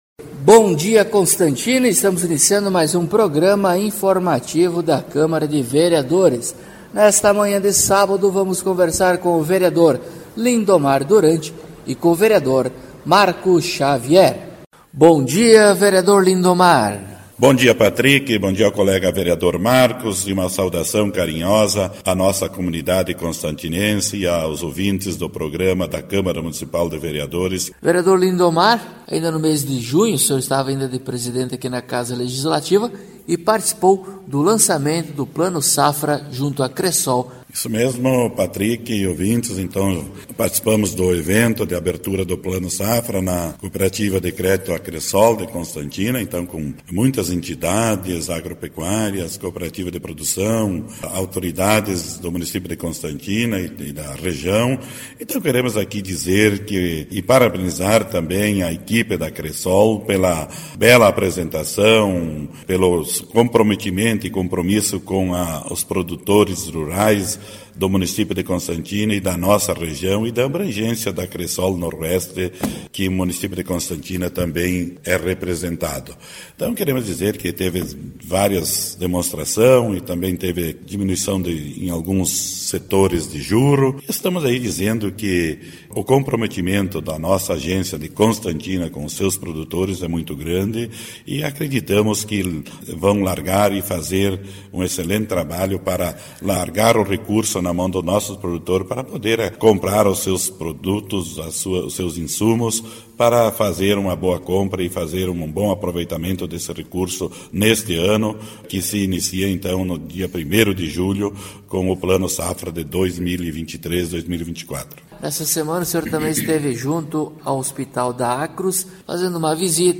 Acompanhe o programa informativo da câmara de vereadores de Constantina com o Vereador Lindomar Duranti e o Vereador Marco Xavier.